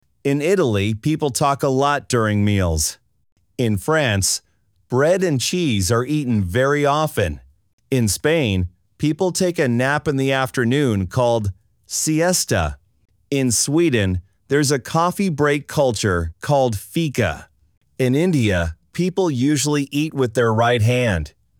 アメリカ人